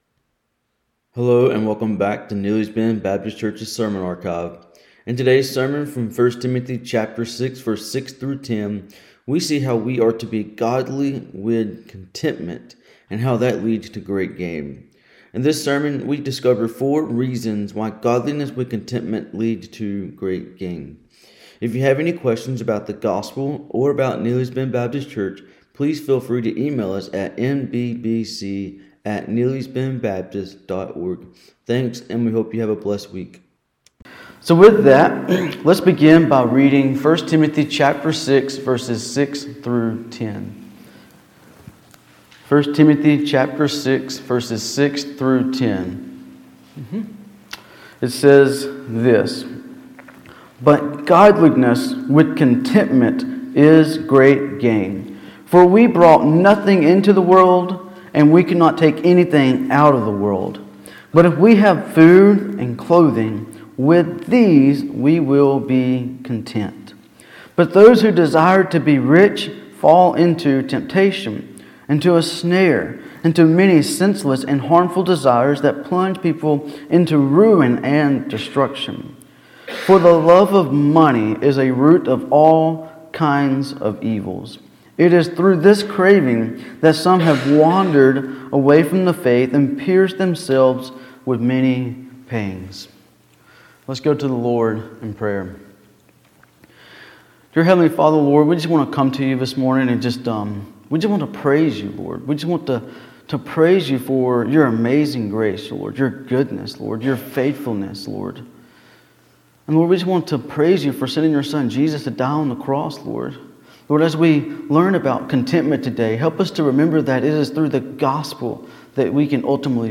In this sermon we see four reasons on how godliness with contentment is great gain.